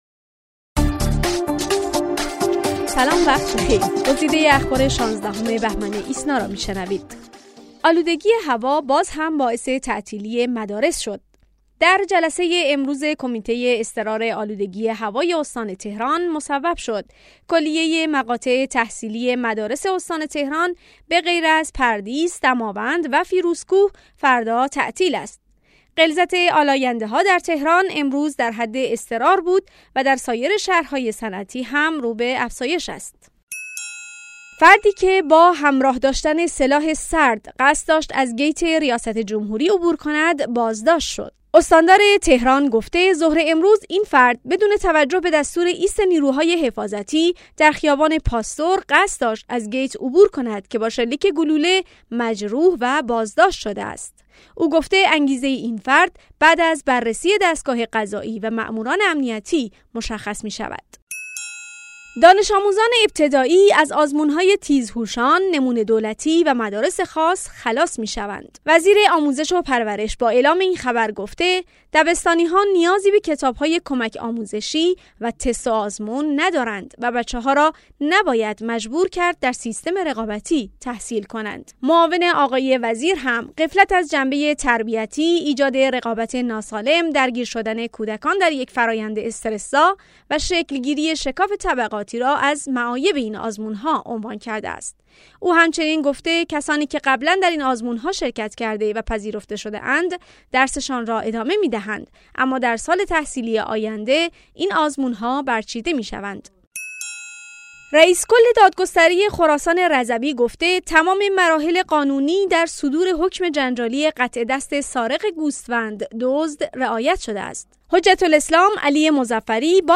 صوت / بسته خبری ۱۶ بهمن ۹۶